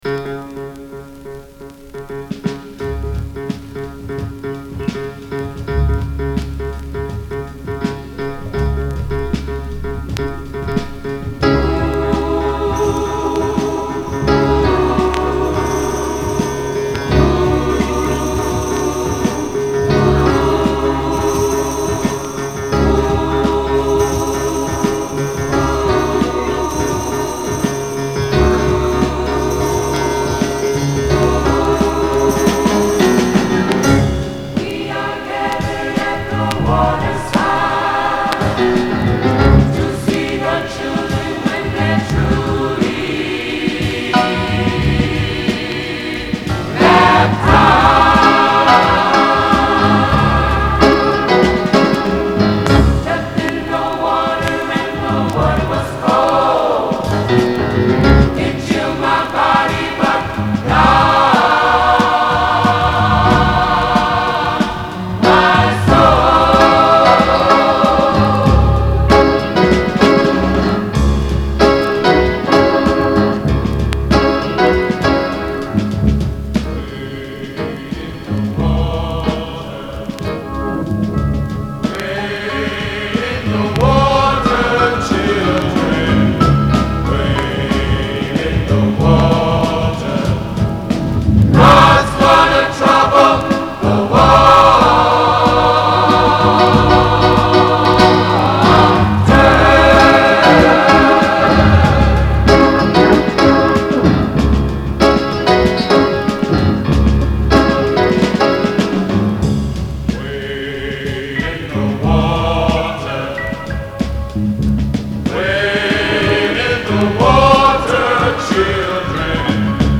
uptempo gospel soul tracks